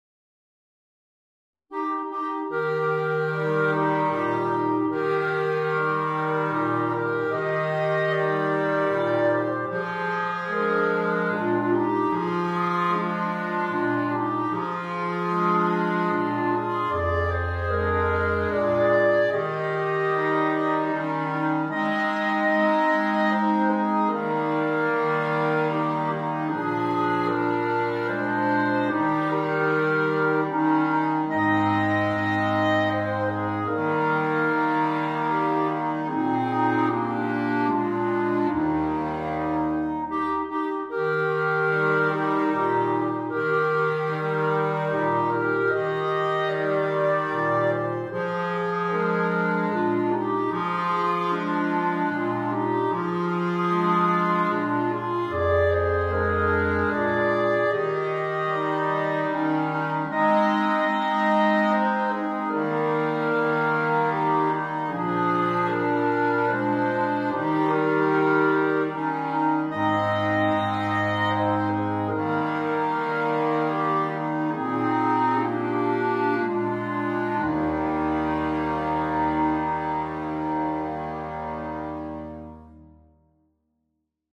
The MP3 was recorded with NotePerformer 3...